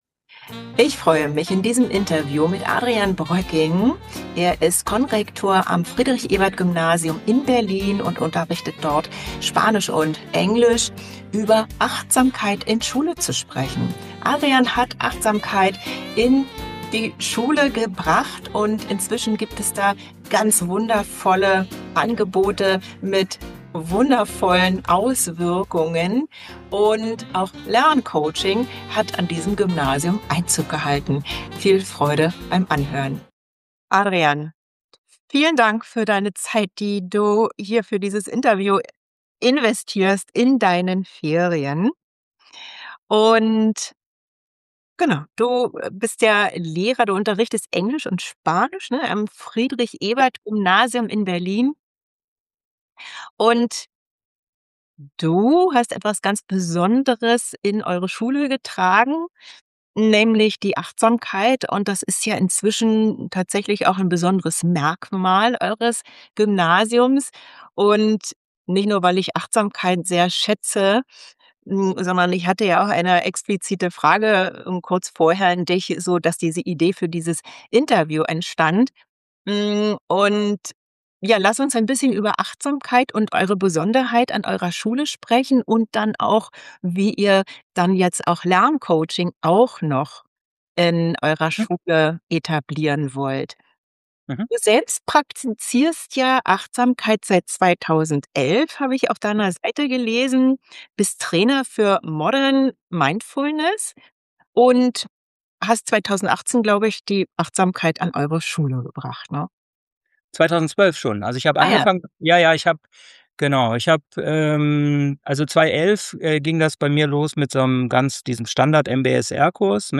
🎥 inkl. Video-Interview zum Anschauen 🎙 oder als Audio zum Anhören: